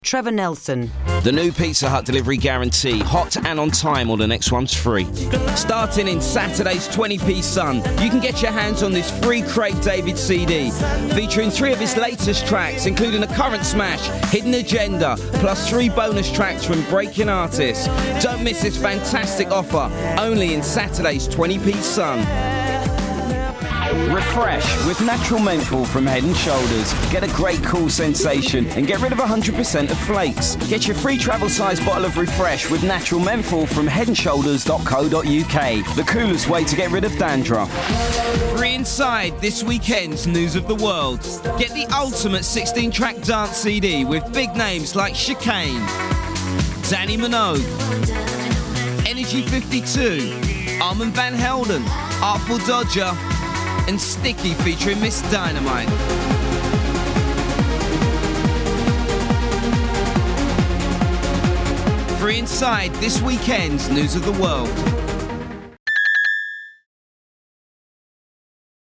40/50's London,
Upbeat/Deep/Recognisable
Trevor-Nelson-Commercial-Showreel.mp3